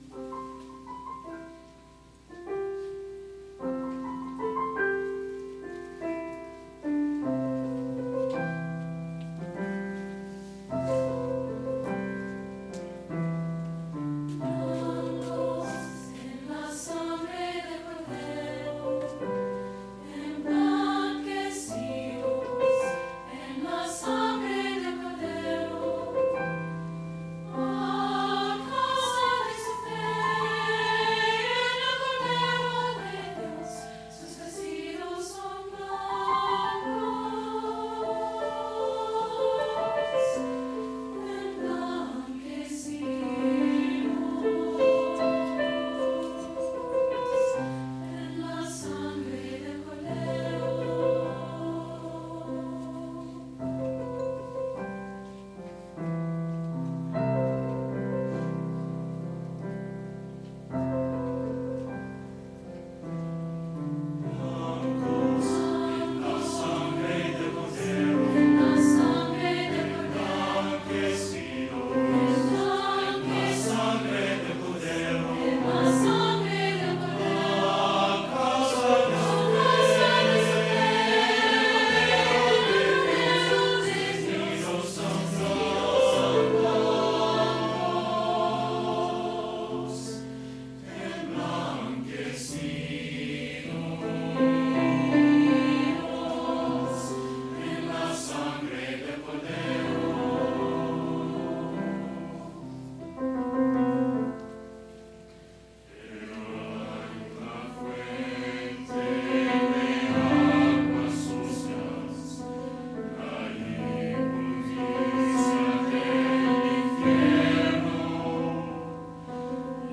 SATB Choir, and Piano